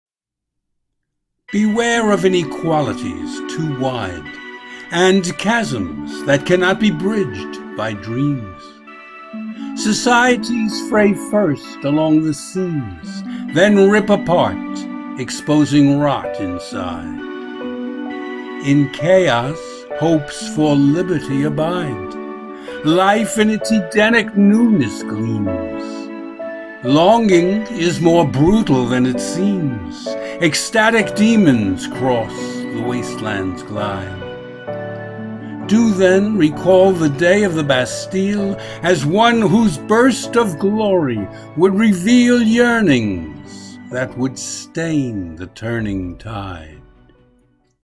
Audio and Video Music:
Music free to use